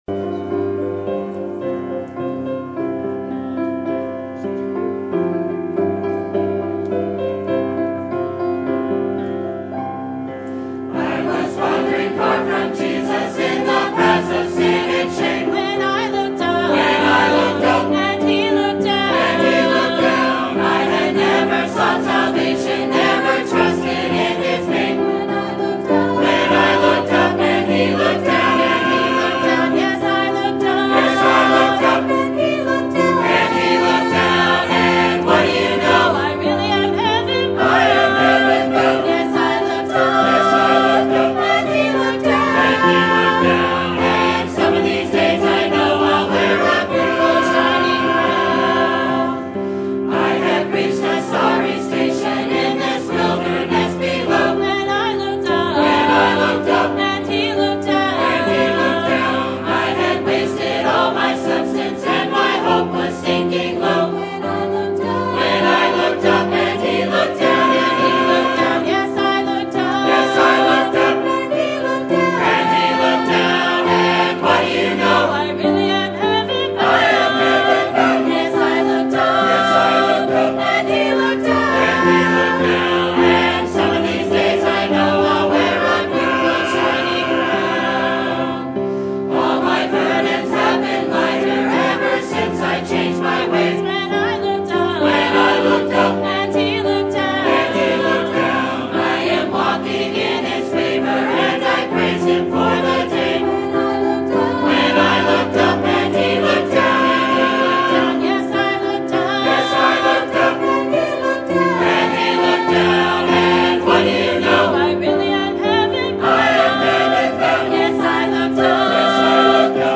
Sermon Topic: General Sermon Type: Service Sermon Audio: Sermon download: Download (22.03 MB) Sermon Tags: Titus Paul Speak Doctrine